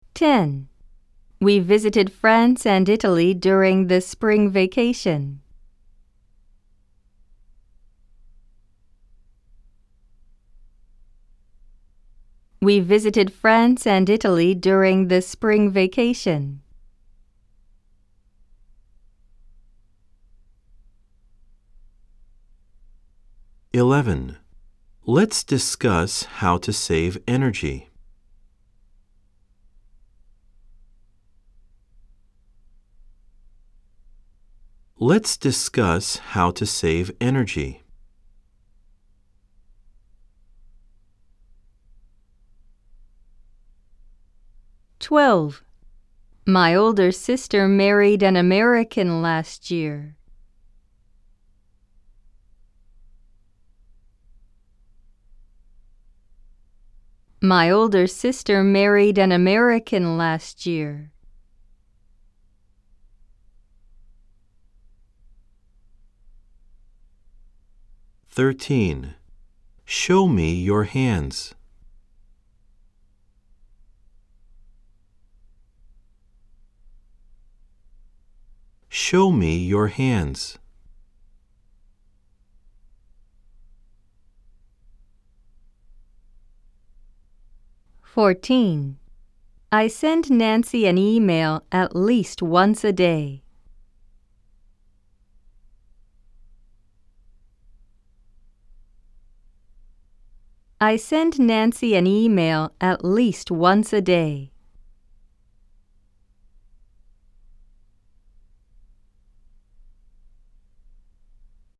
（3）暗唱例文100　各章別ファイル（英文2回読み）
※（1）（2）では英文のあとに各5秒のポーズ、（3）（4）では各7秒のポーズが入っています。